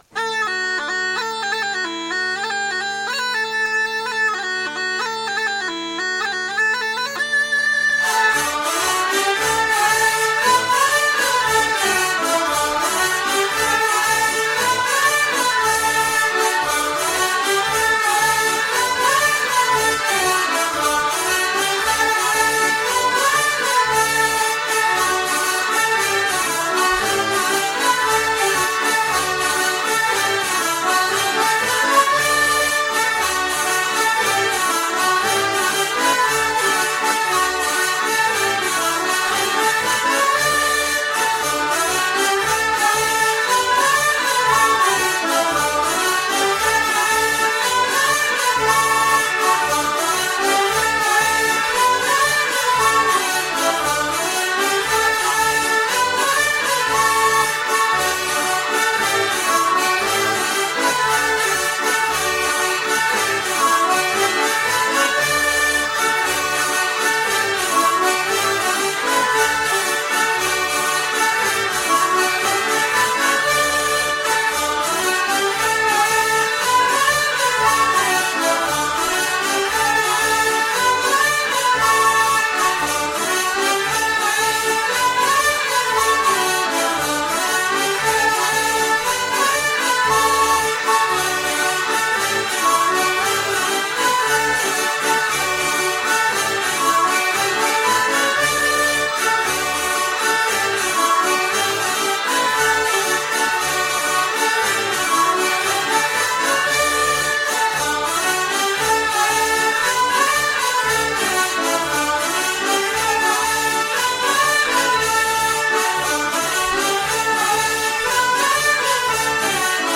Bourrée de St Chartier (2 temps)